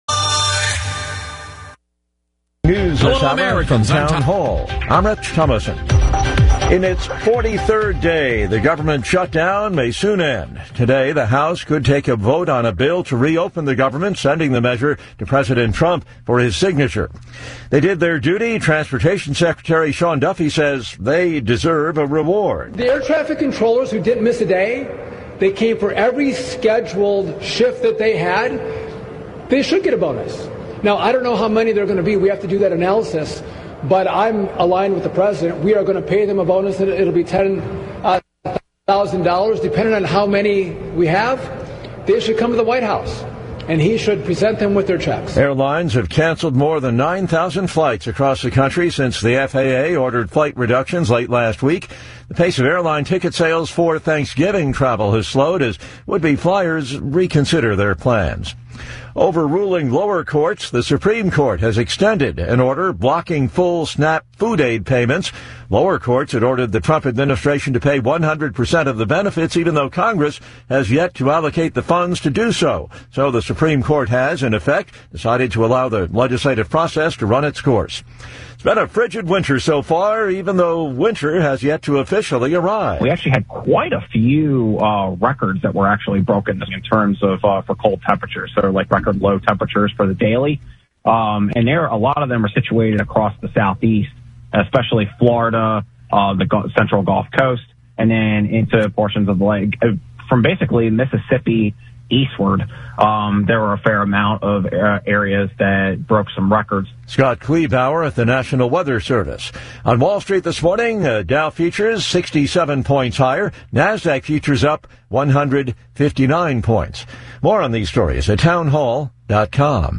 in-studio